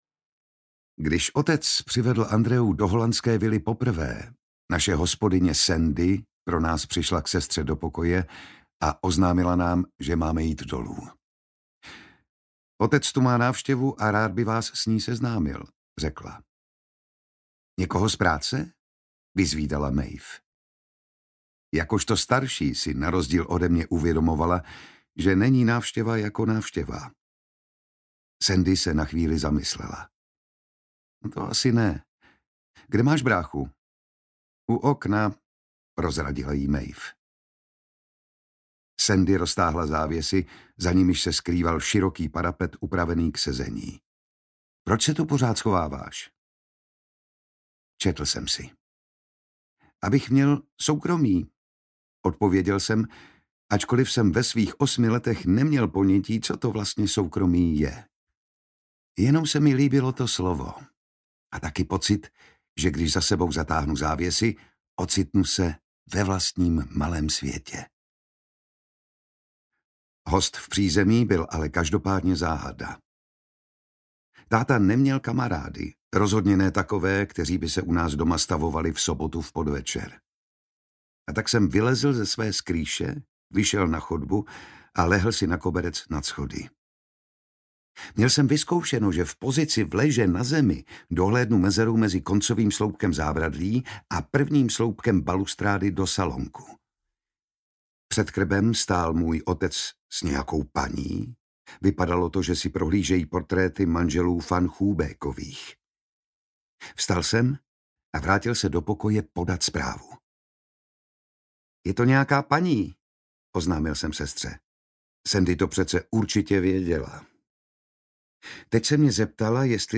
Audiobook
Read: Jan Šťastný